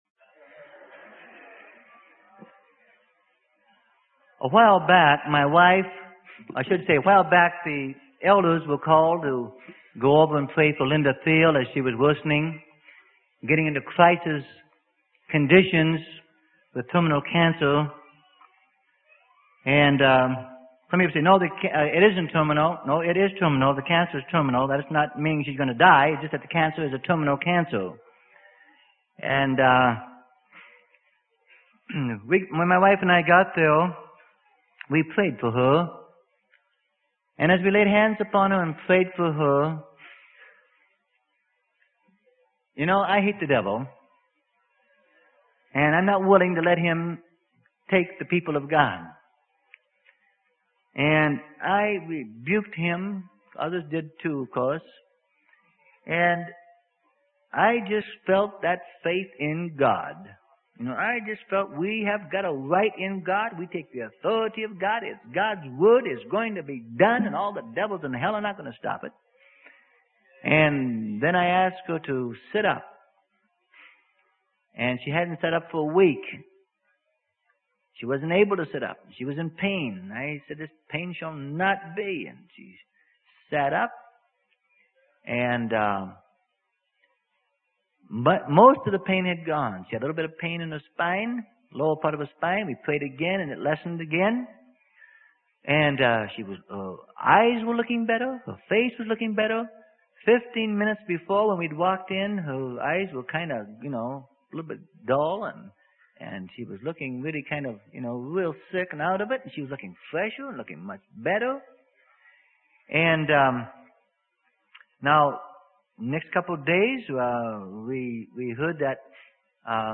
Sermon: Liberty in the Holy Ghost - Freely Given Online Library